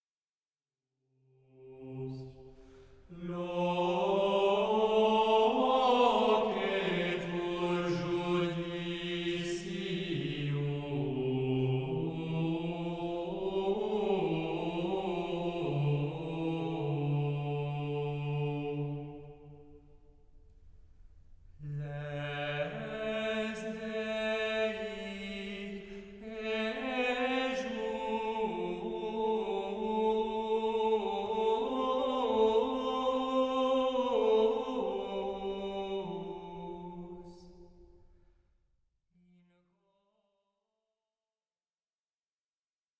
Plain-chant et polyphonies du 14e siècle
Graduel